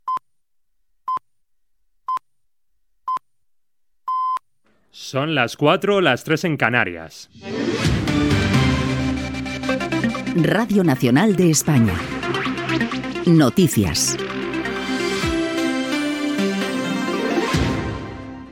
Senyals horaris, hora i careta del butlletí informatiu.
Informatiu
FM